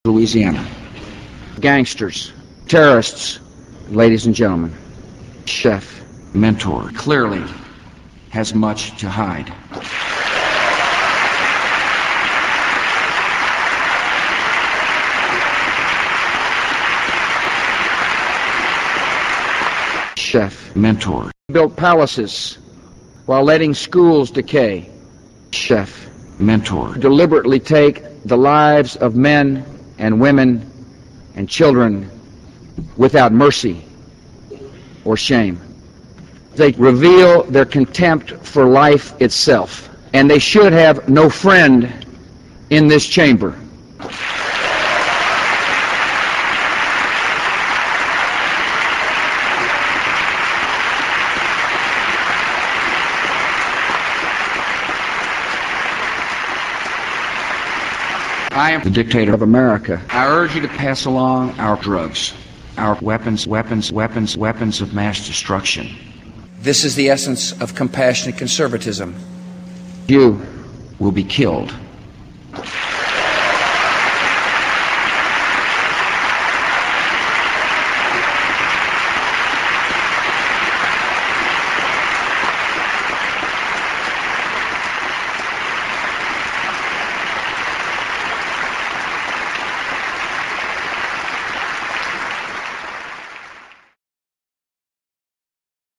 Our Holy Leader makes a speech to the United Nations, a major part of which is a